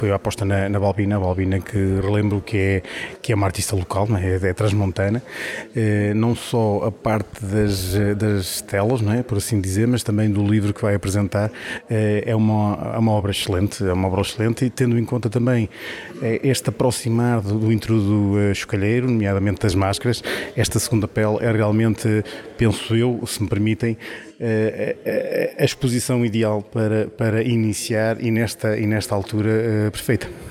Presente na inauguração, o presidente da Câmara Municipal de Macedo de Cavaleiros, Sérgio Borges, sublinhou a importância simbólica desta exposição e do livro, numa altura que antecede o Entrudo Chocalheiro: